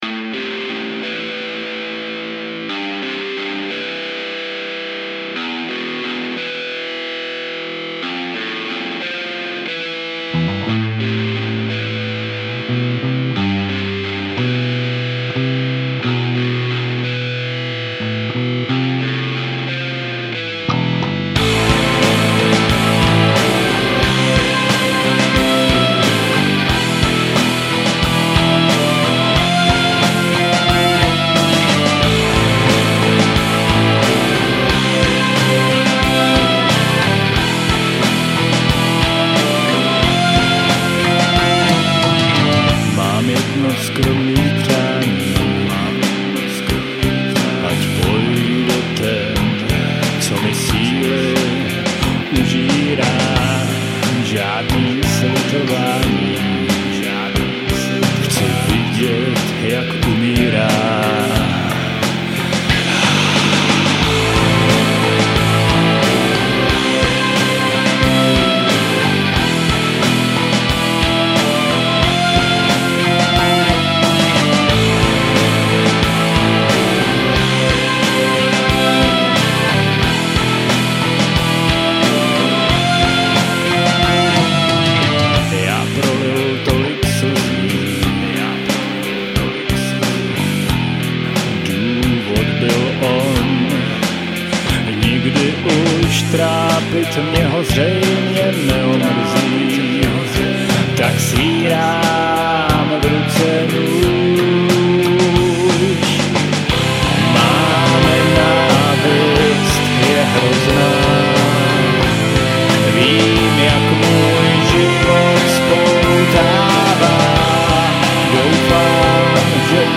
Žánr: Metal/HC
Třískladbové demo gothic rock/metalového projektu.